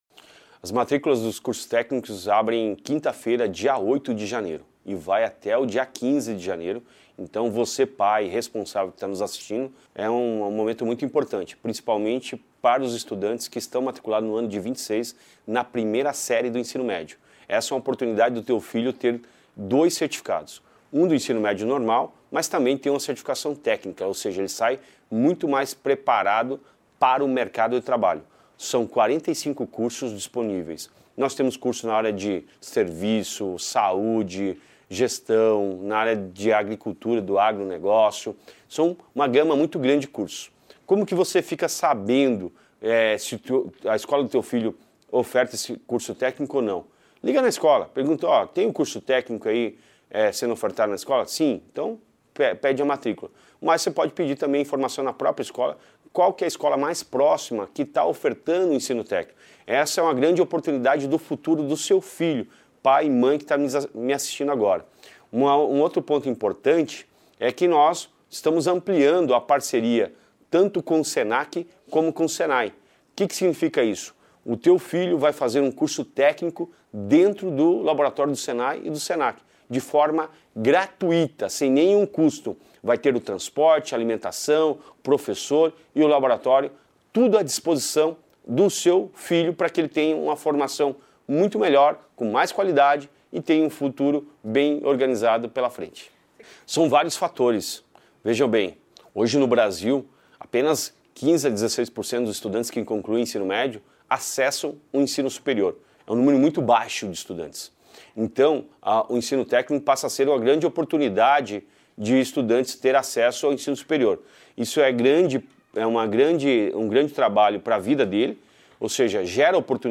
Sonora do secretário da Educação, Roni Miranda, sobre a reabertura das matrículas para cursos técnicos